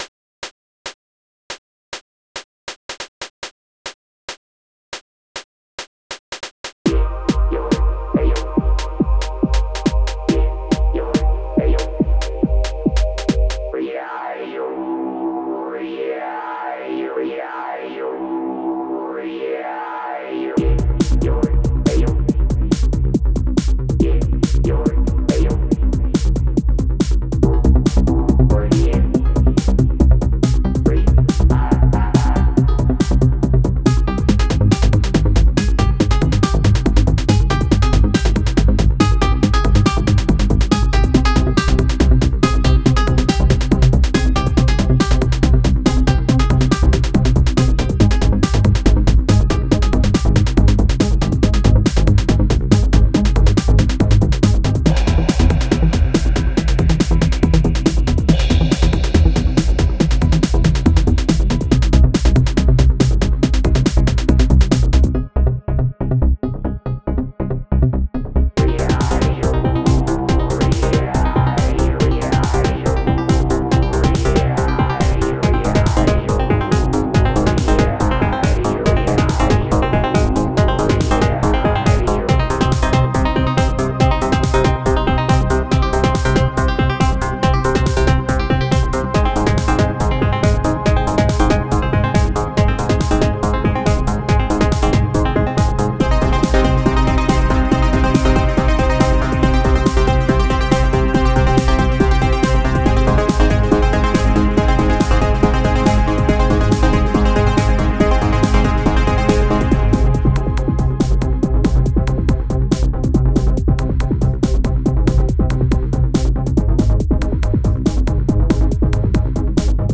lmms electronic trance music https